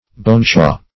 boneshaw - definition of boneshaw - synonyms, pronunciation, spelling from Free Dictionary Search Result for " boneshaw" : The Collaborative International Dictionary of English v.0.48: Boneshaw \Bone"shaw\ (-sh[add]), n. (Med.) Sciatica.